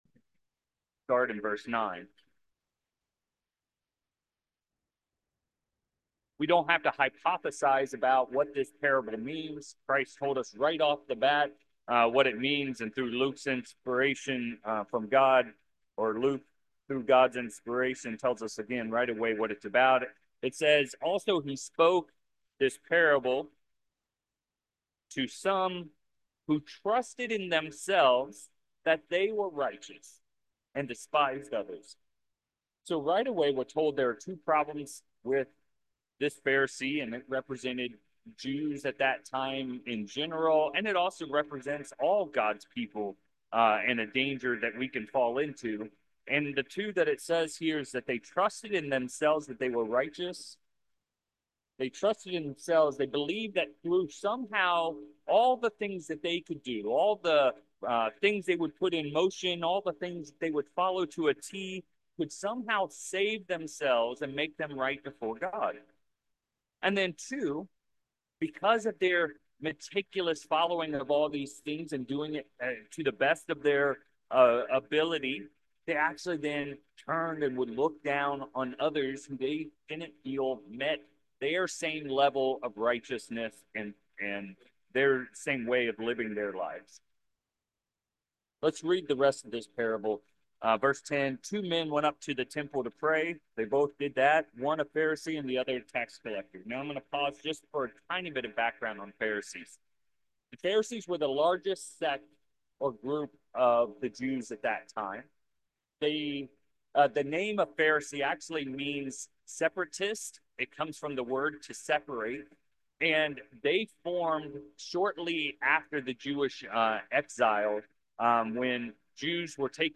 4/29/24 Please note, that the first few seconds are cut off, and we immediately turn to Luke 18:9. In this sermon we look at the critical importance of taking in the Unleavened Bread of Jesus Christ. Is Christ in us just a neat metaphor or is it much more?